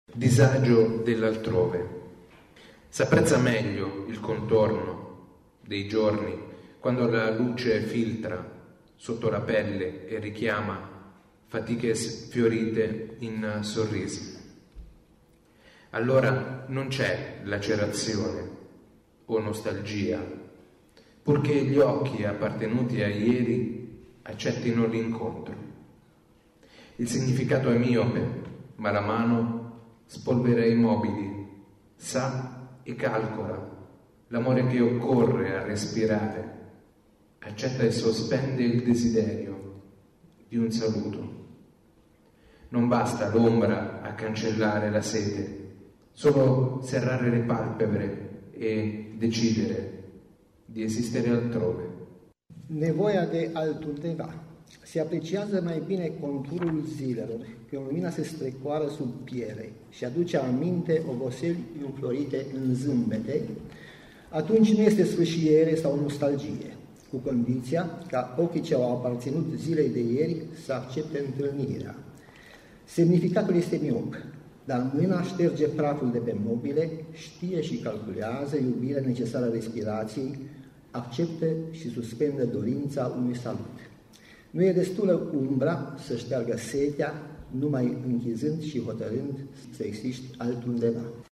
În versiune bilingvă, română-italiană, poemele celor doi au fost rostite în premieră la Sighişoara.